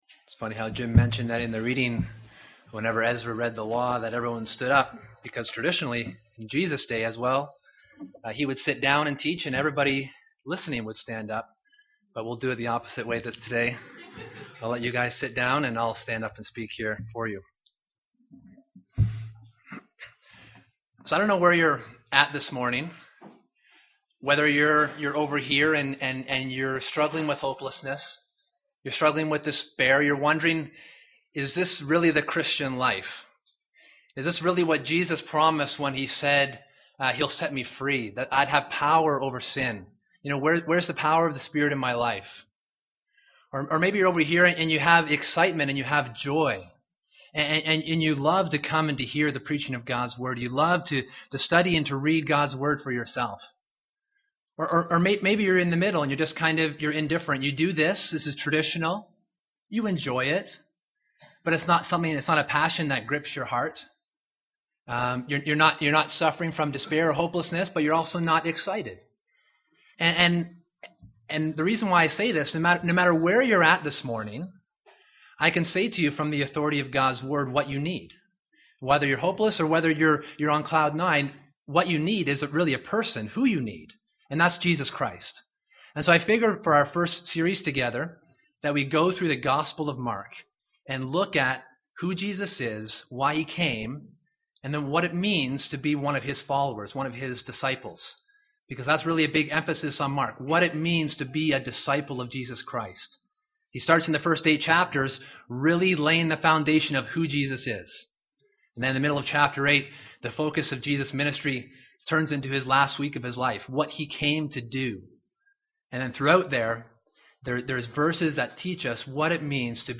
This book was given to make and encourage disciples of Jesus, and this sermon series seeks to do the same by proclaiming Mark's message for today's generation.